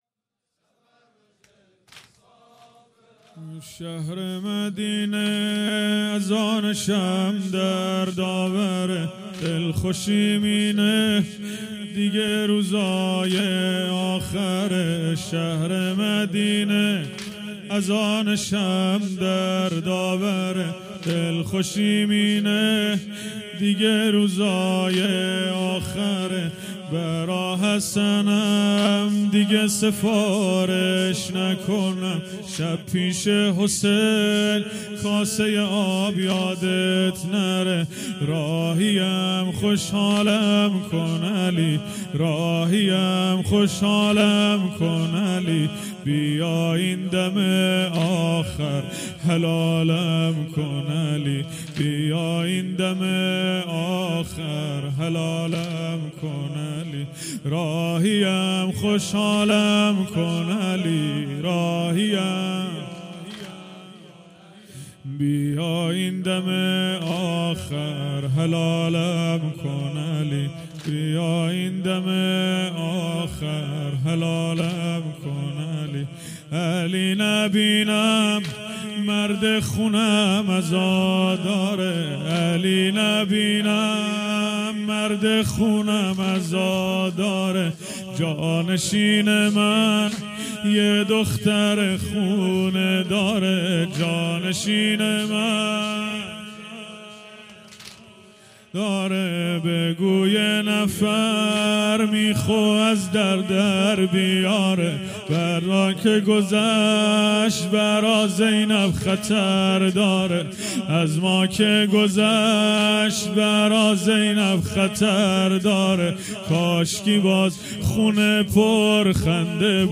هیئت حسن جان(ع) اهواز - واحد | شهر مدینه اذانشم درد آوره
شب چهارم فاطمیه اول ۱۴۰۱